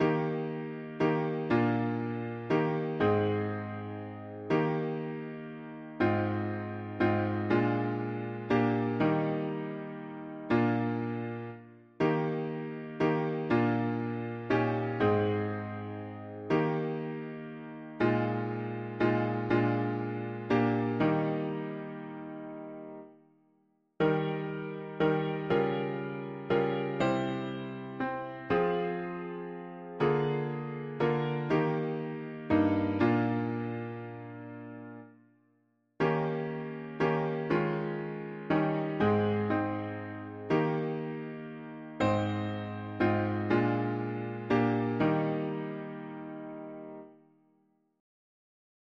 Er, der nie begonnen, or, der immer war, ewig ist und walte… german english christian 4part